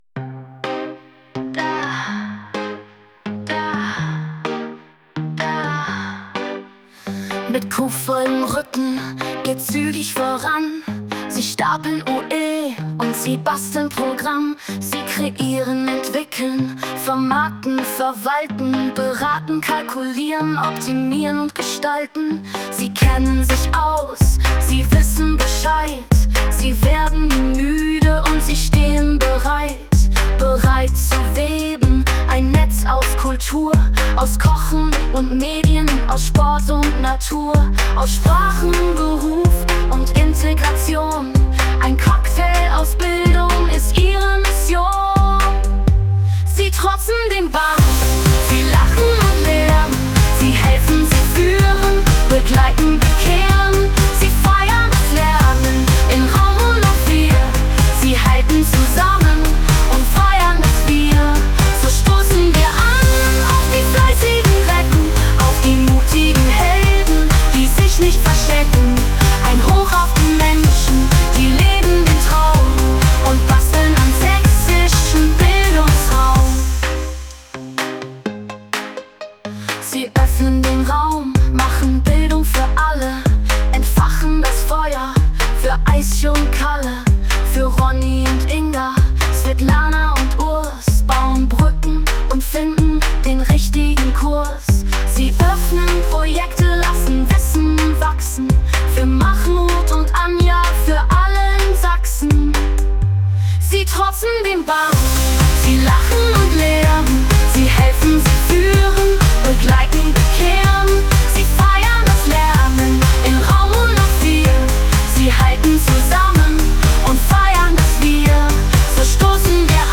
Der Song beschreibt und würdigt die wertvolle Arbeit der vhs-Mitarbeiter*innen. Er wurde bereits beim 1. Sächsischen vhs-Tag in Chemnitz vorgestellt.